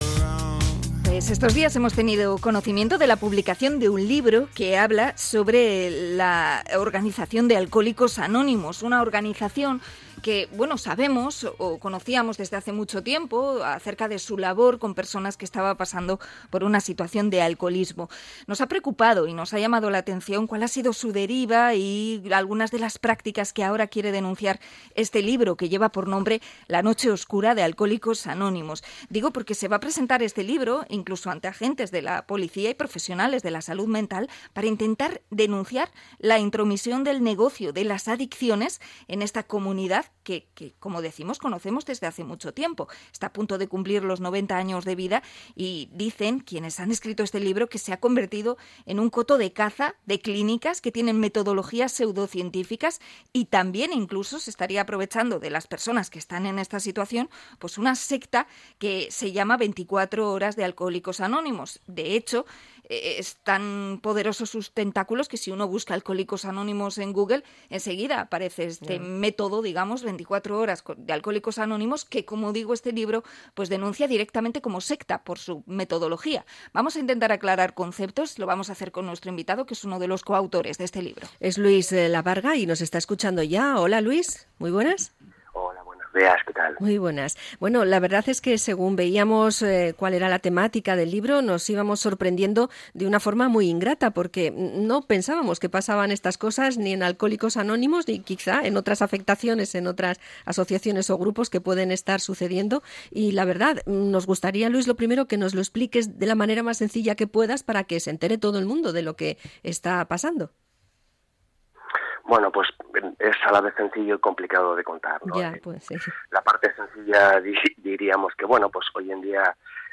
Entrevista
INT.-ALCOHOLICOS-ANONIMOS.mp3